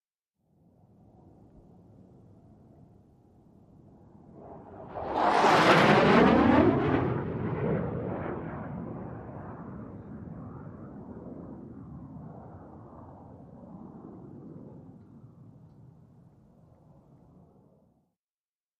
F-16 Fighting Falcon
F-16 Fly By Very Fast